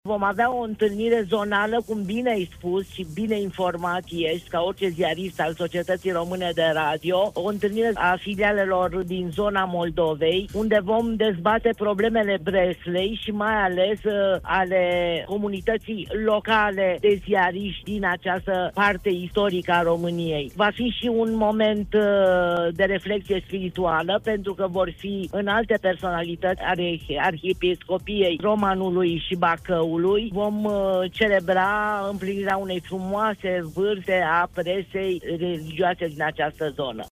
în interviul oferit colegului nostru de la Radio România Iași